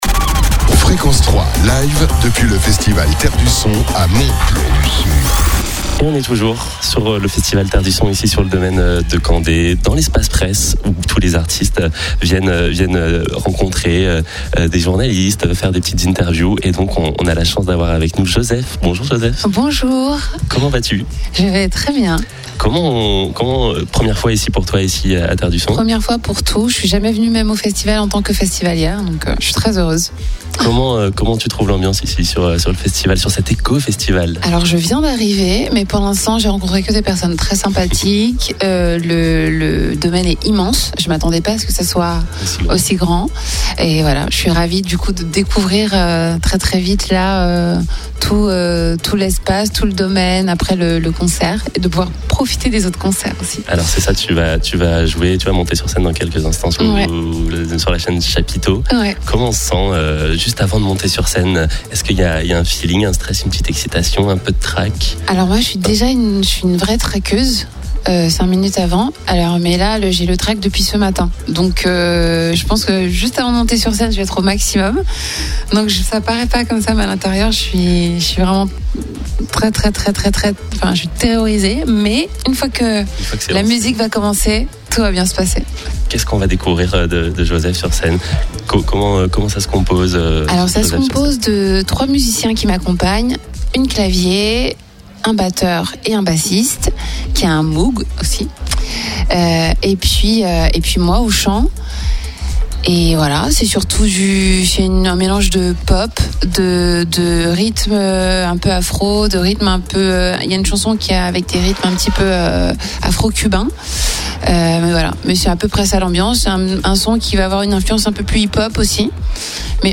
au festival Terres du Son